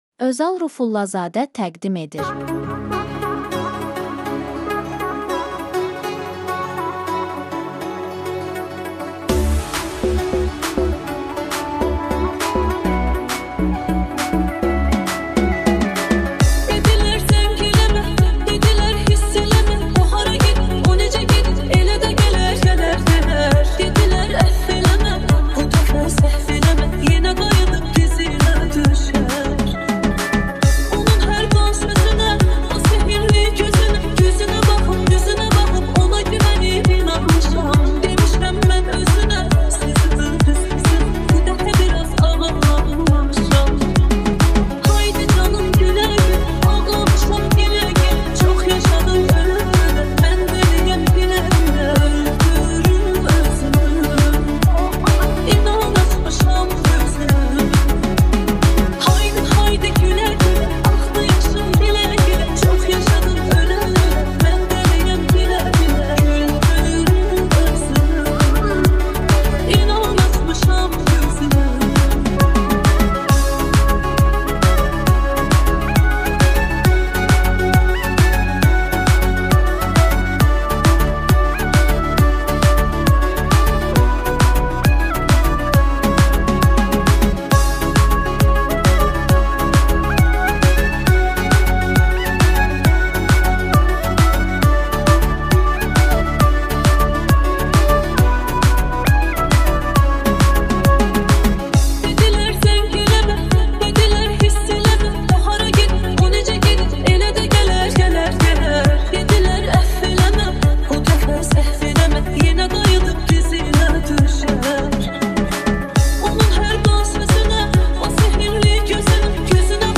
ورژنی متفاوت و انرژی‌بخش از یک قطعه احساسی محبوب.